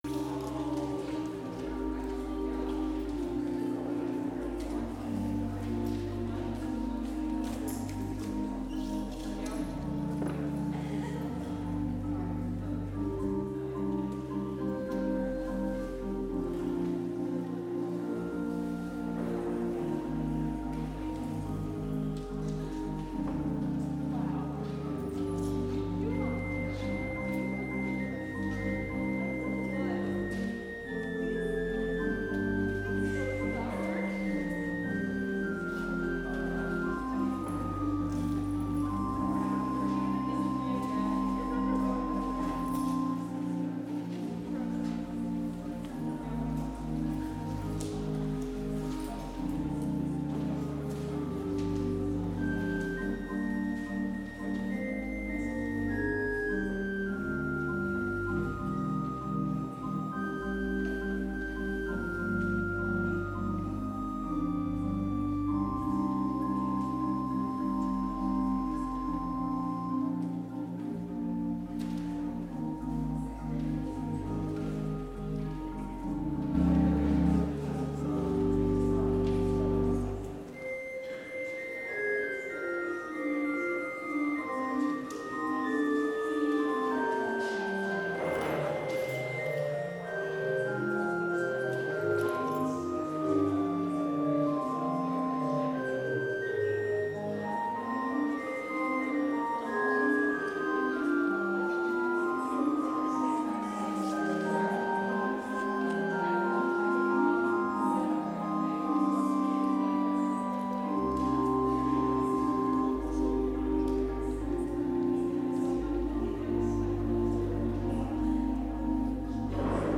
Complete service audio for Chapel - January 27, 2021